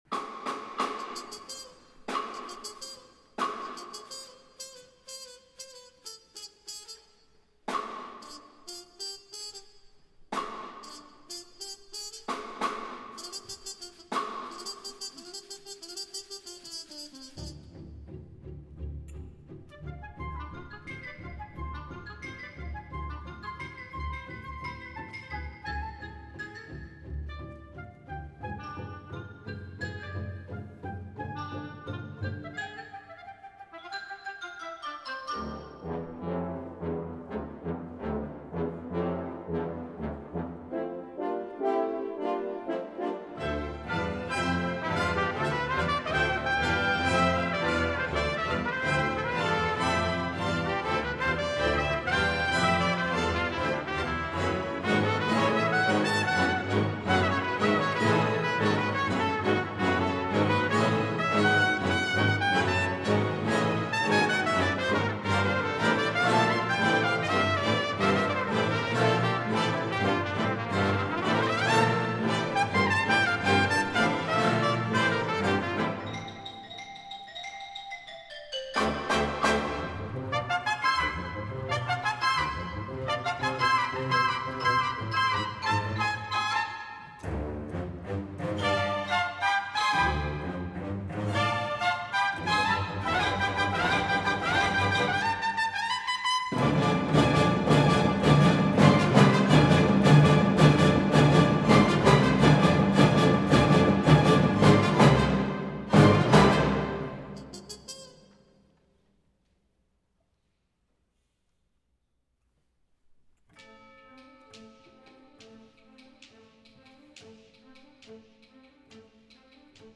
tpt